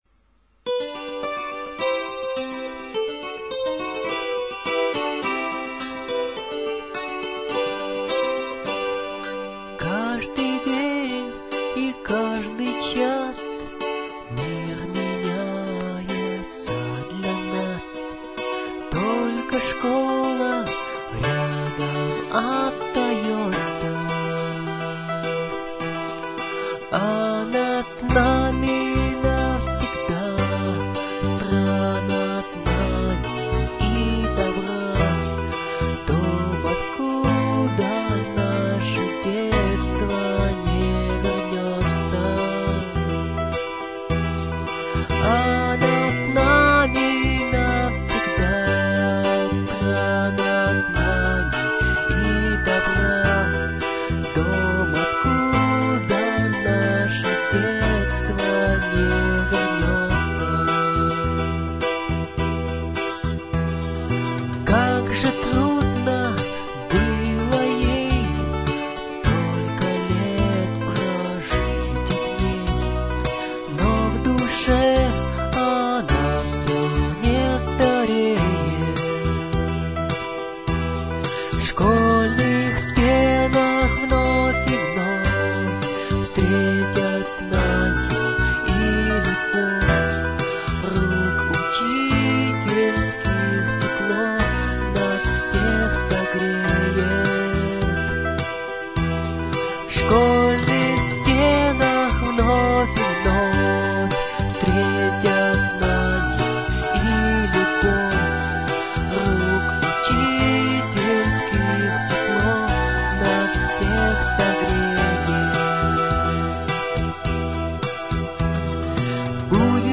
Гимн школы